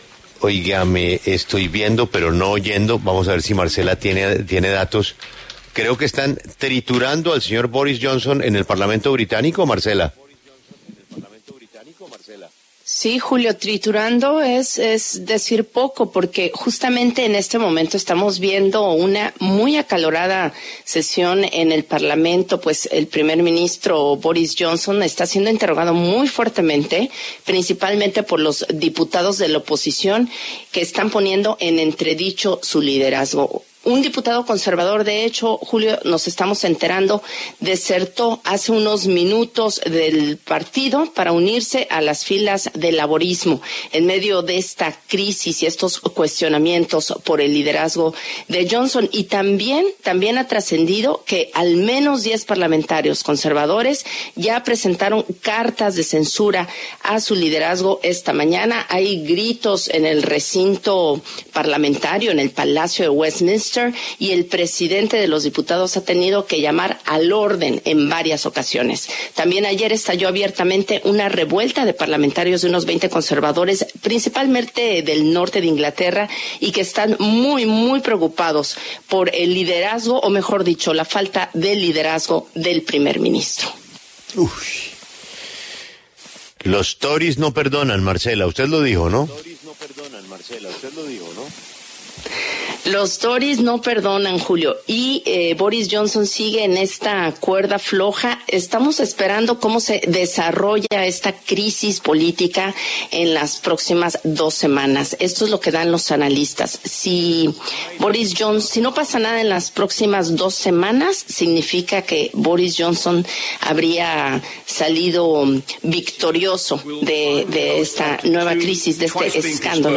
Se trata de una sesión de control semanal en la Cámara de los Comunes donde se enfrenta a fuertes críticas por su liderazgo.
W Radio también reveló que el recinto del Palacio de Westminster hubo gritos y hasta el presidente del Parlamento tuvo que intervenir para calmar los ánimos.